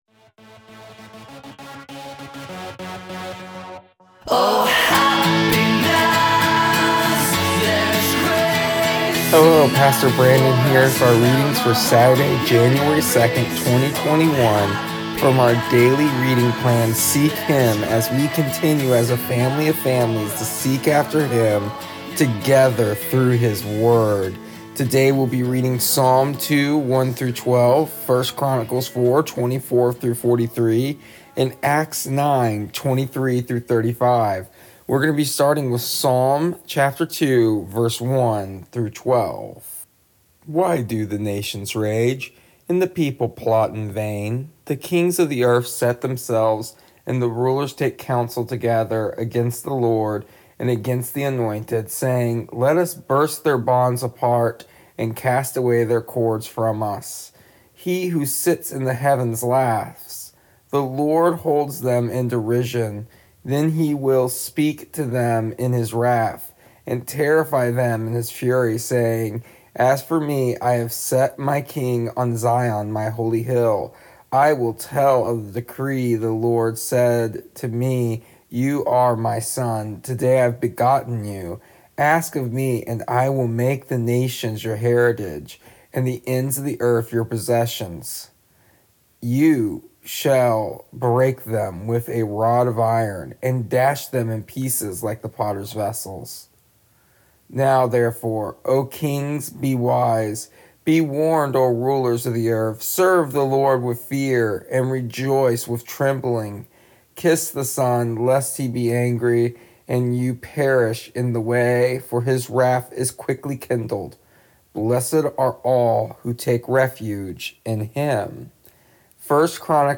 Here is the audio version of our daily readings from our daily reading plan Seek Him for January 2nd, 2021.